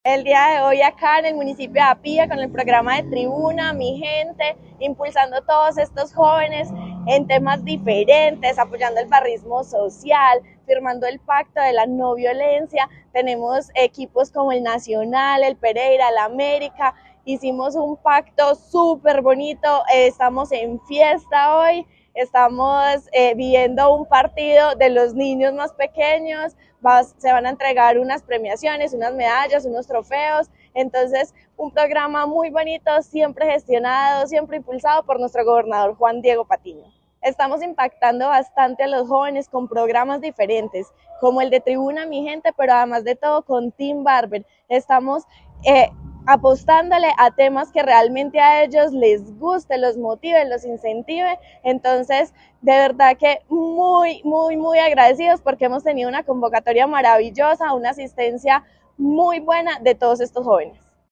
Escuchar Audio: Stephania Agudelo, Secretaria De Mujer, Familia Y Desarrollo Social.